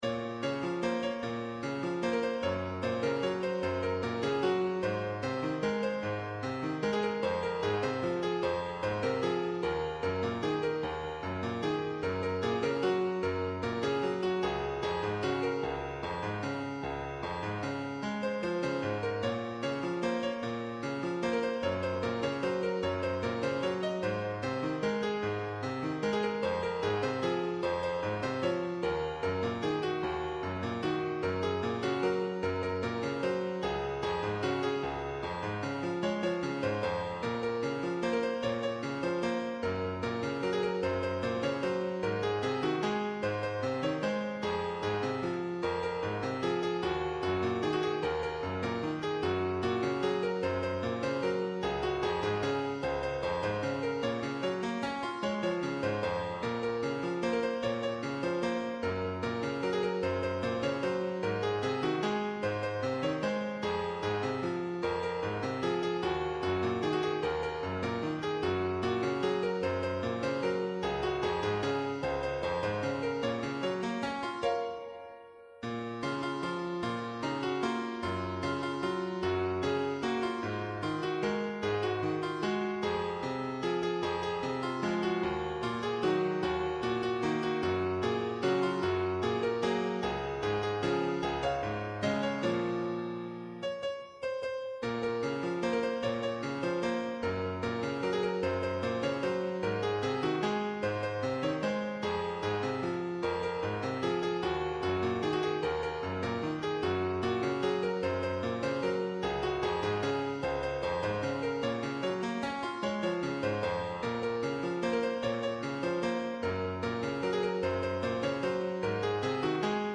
تنظیم شده برای پیانو